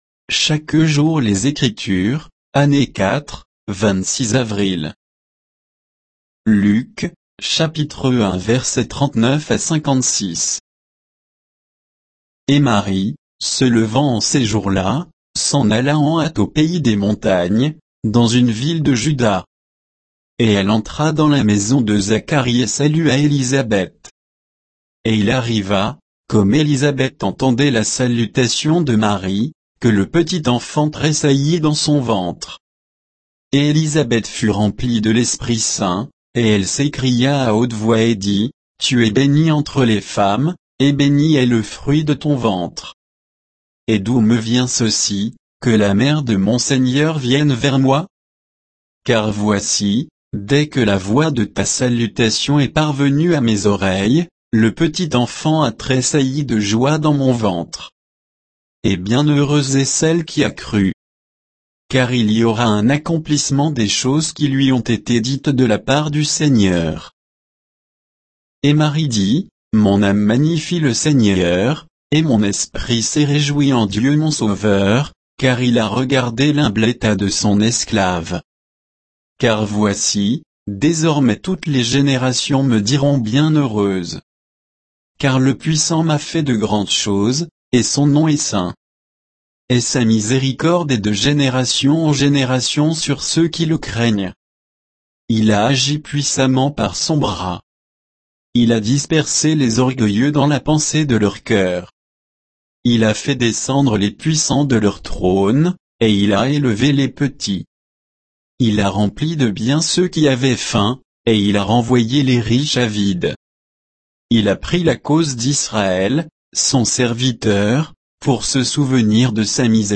Méditation quoditienne de Chaque jour les Écritures sur Luc 1